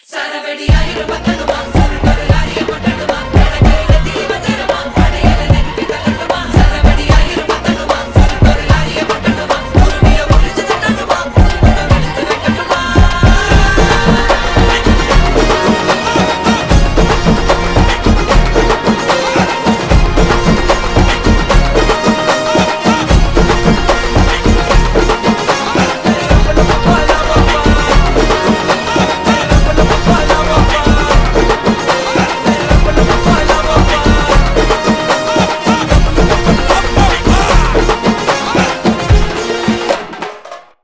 extended beats